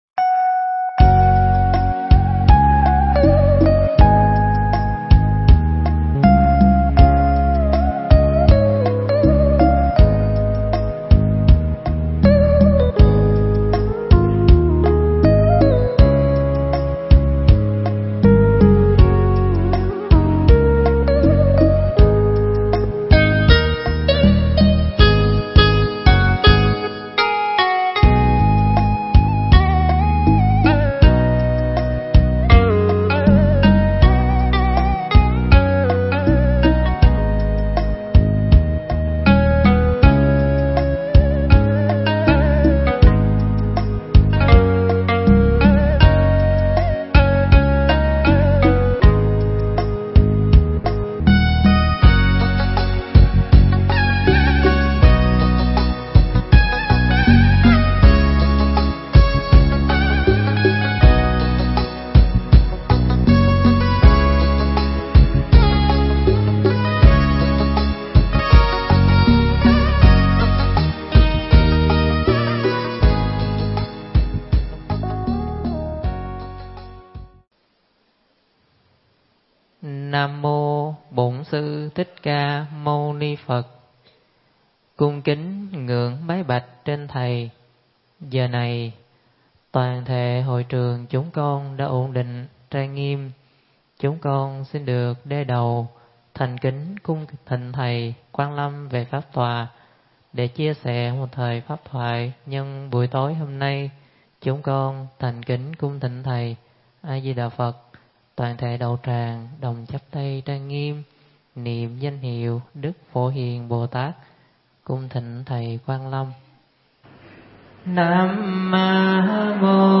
Mp3 Thuyết Pháp Kinh Pháp Cú Phẩm Địa Ngục
giảng tại Tu Viện Tường Vân